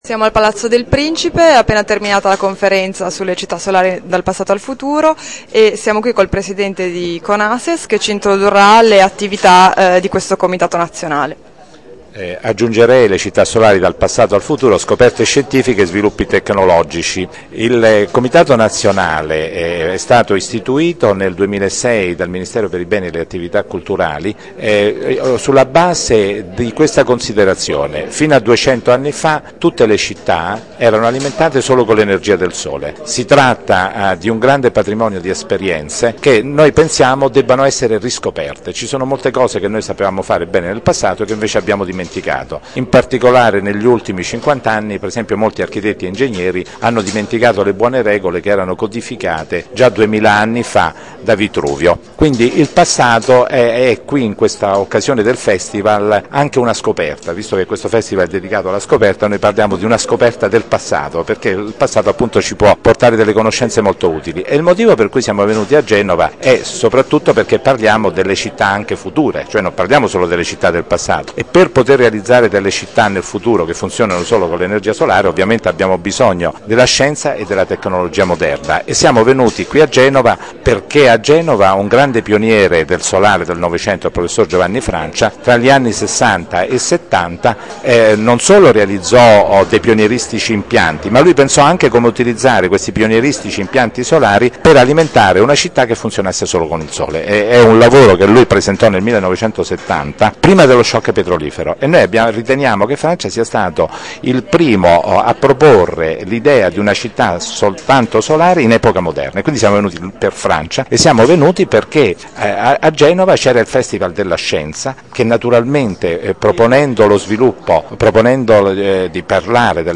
Intervista ad uno degli animatori scientifici del Festival della Scienza (1 MB)